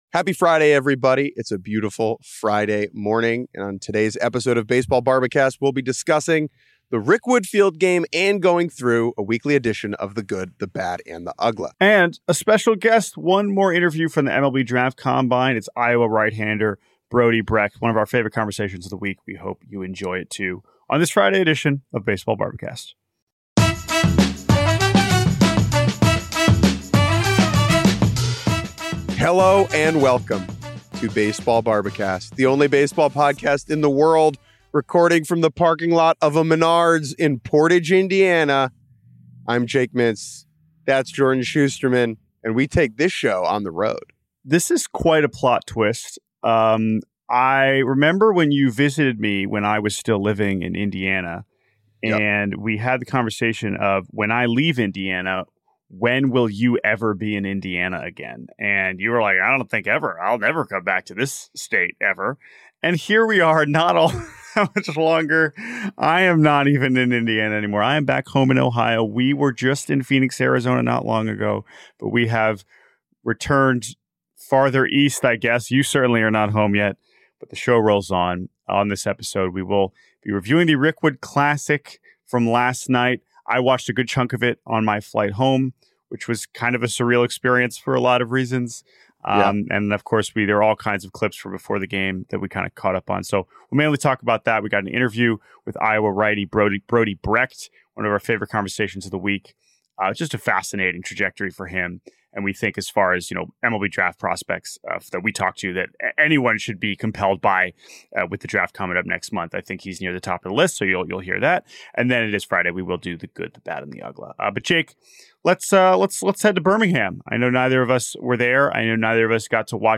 Rickwood Field game recap